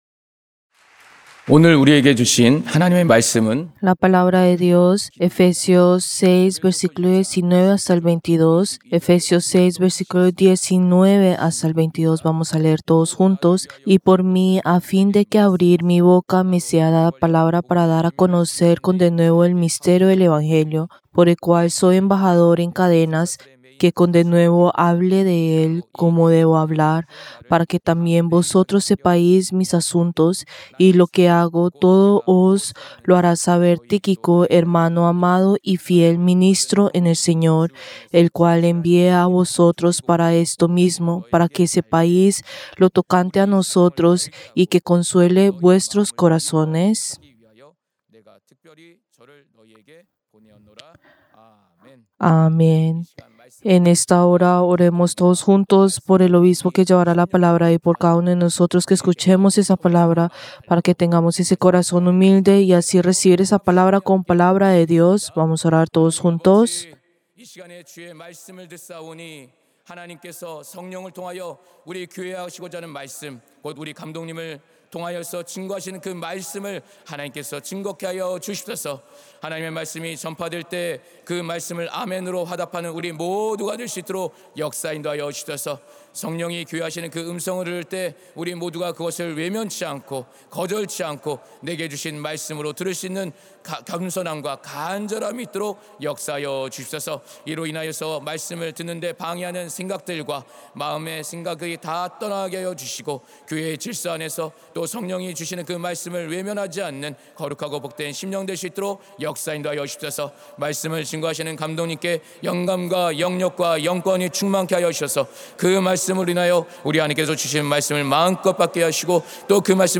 Servicio del Día del Señor del 17 de agosto del 2025